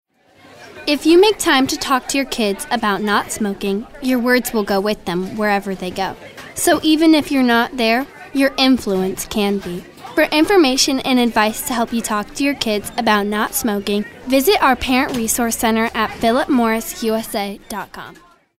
anti-announcer, confident, conversational, cool, friendly, genuine, girl-next-door, kid-next-door, real, teenager, thoughtful, young, younger